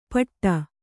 ♪ paṭṭa